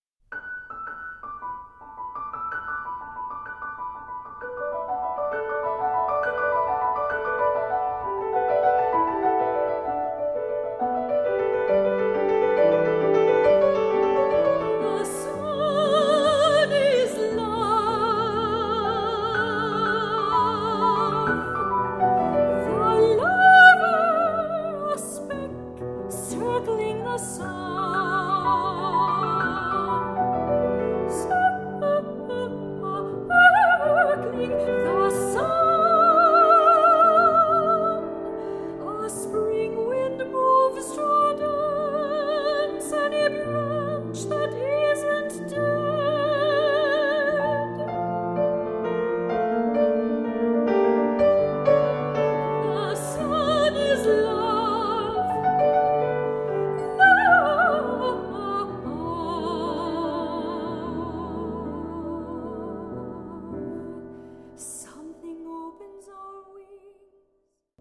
for High Voice and Piano (2002)
soprano
piano.